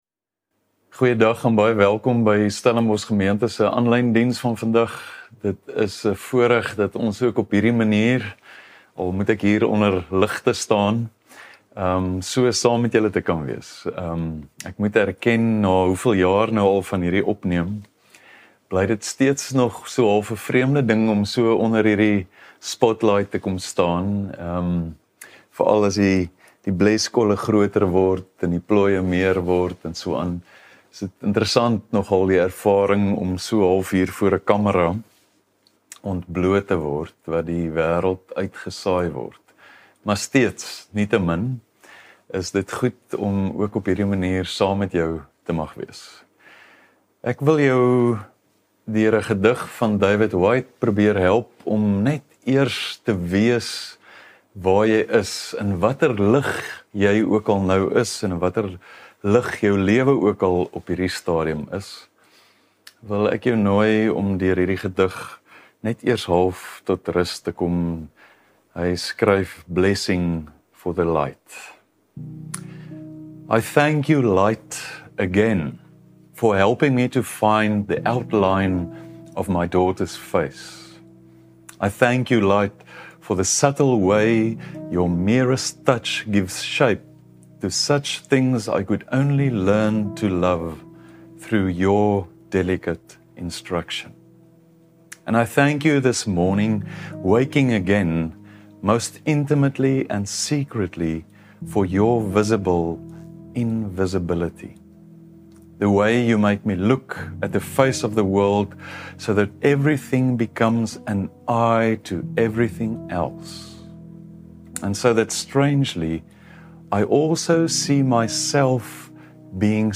Stellenbosch Gemeente Preke 20 Oktober 2024 || God Is...